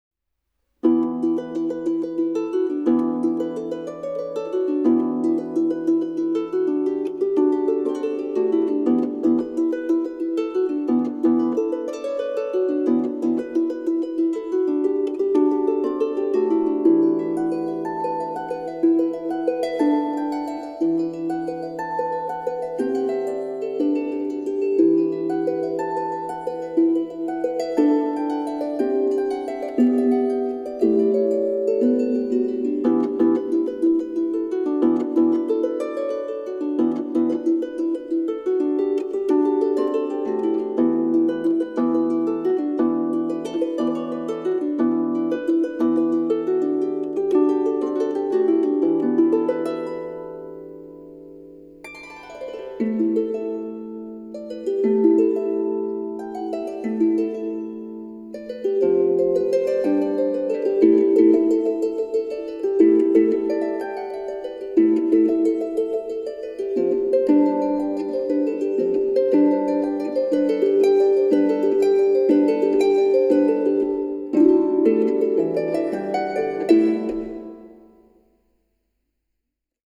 Advanced level sheet music for small Celtic harp.